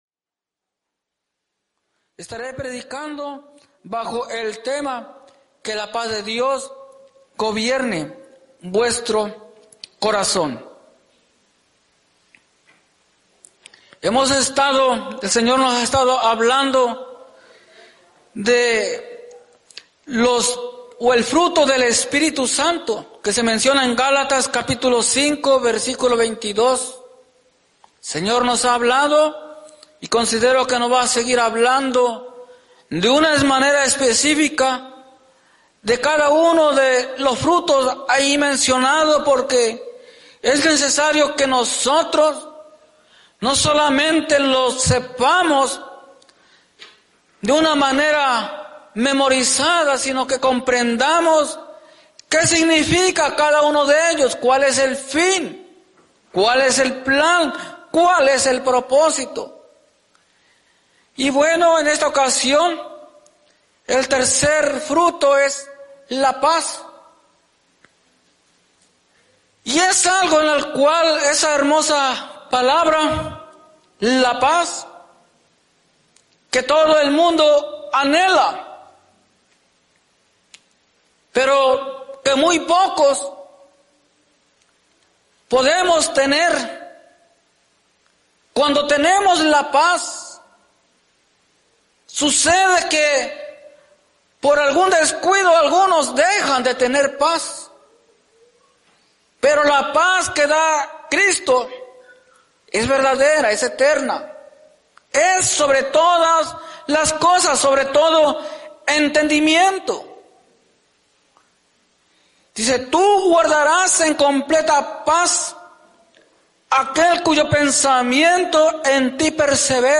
Que la paz de Dios gobierne en vuestros corazones Predica